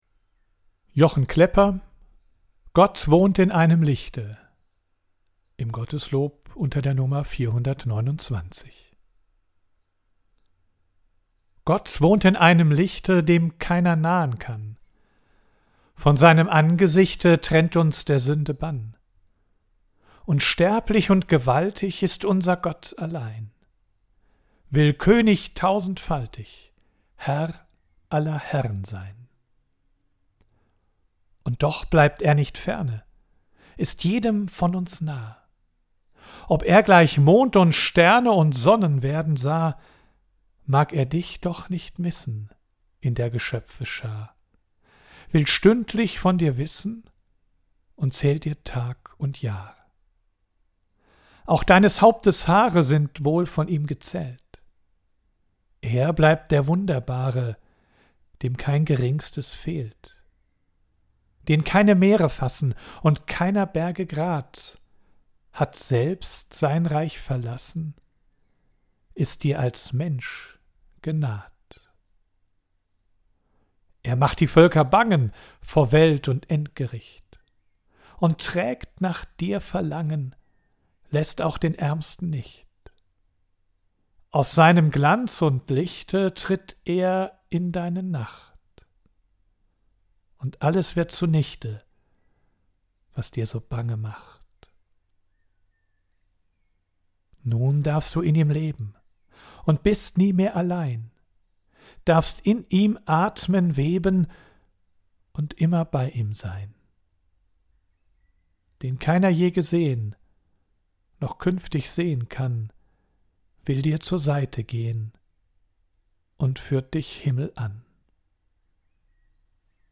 Audio II: Das ganze Gedicht